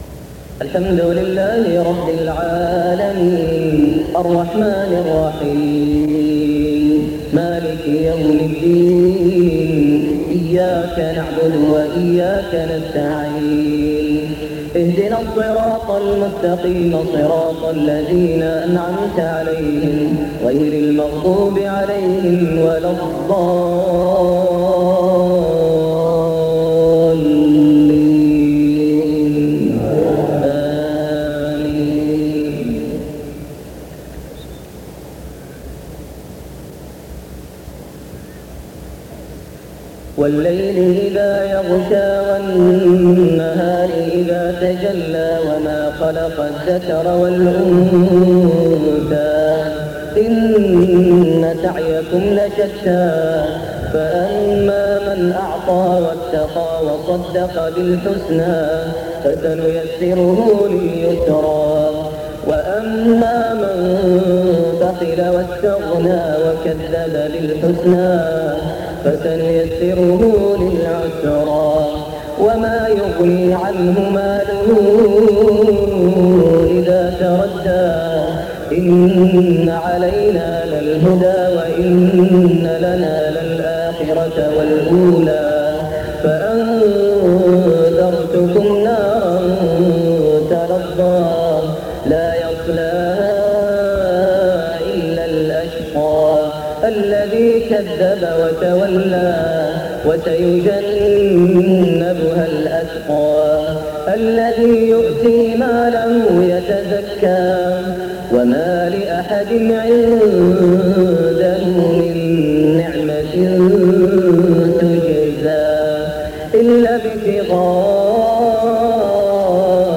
صلاة المغرب 17 محرم 1429هـ سورتي الليل و الزلزلة > 1429 🕋 > الفروض - تلاوات الحرمين